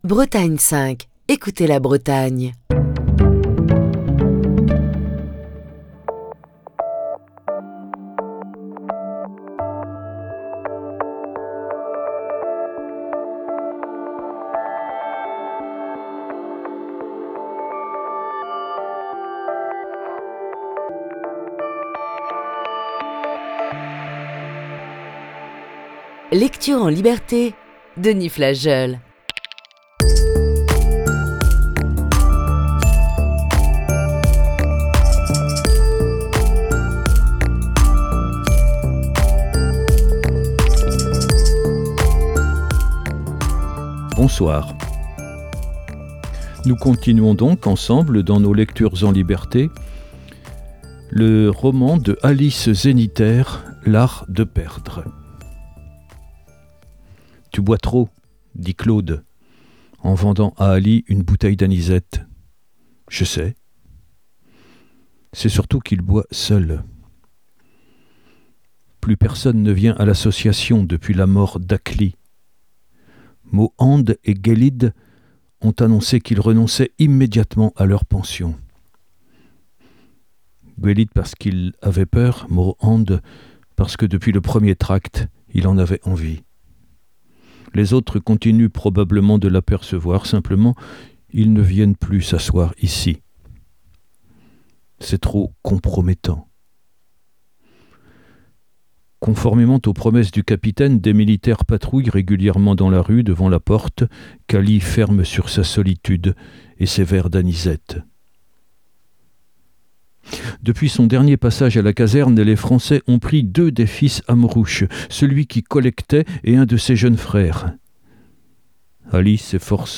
Émission du 10 février 2022.